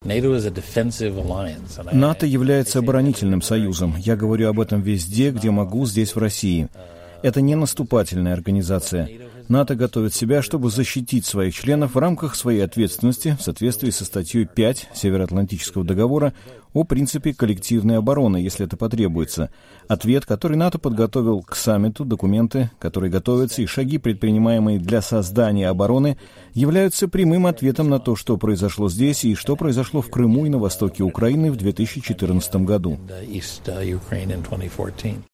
Россия совершила акт агрессии против Украины, а все последующие действия НАТО стали ответом на эти действия, заявил посол США в России Джон Теффт в интервью корреспондентам Радио Свобода/Радио Свободная Европа и "Голоса Америки".